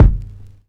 break_kick_2.wav